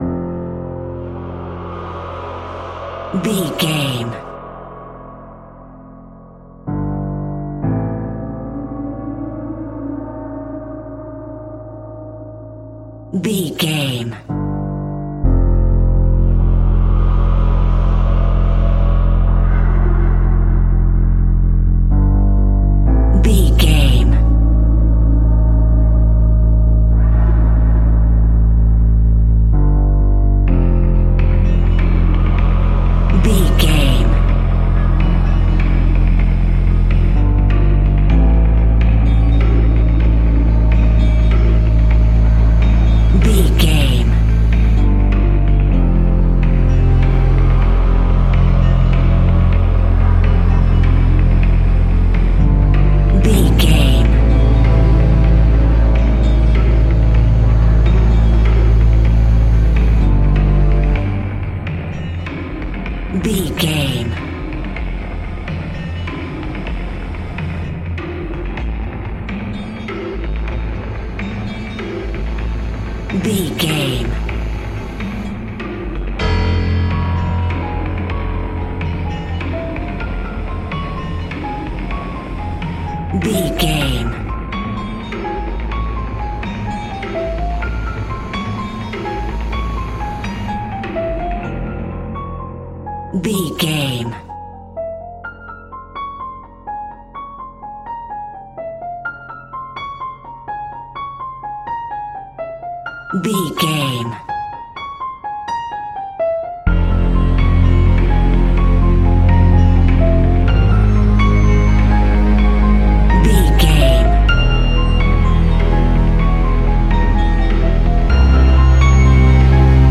In-crescendo
Thriller
Aeolian/Minor
scary
tension
ominous
suspense
haunting
eerie
strings
piano
synthesiser
percussion
drums
ambience
pads